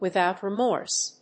アクセントwithòut remórse